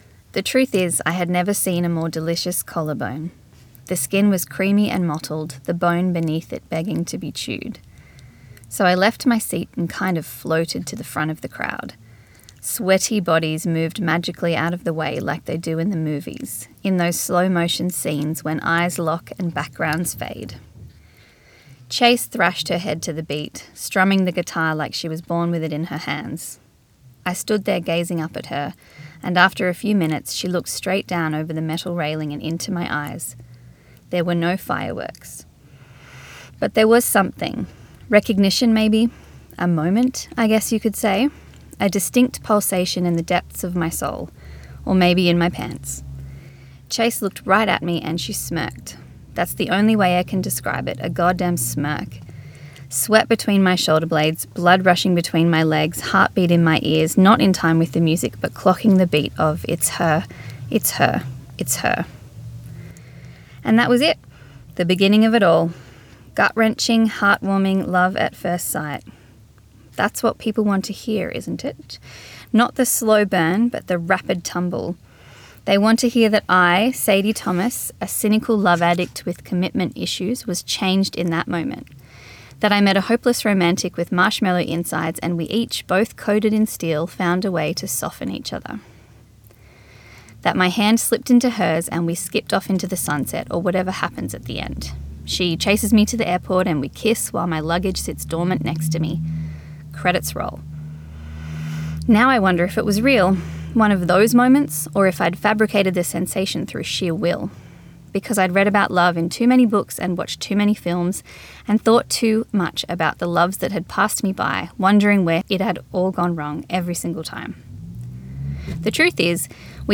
Recorded at Bellingen Readers and Writers Festival 2025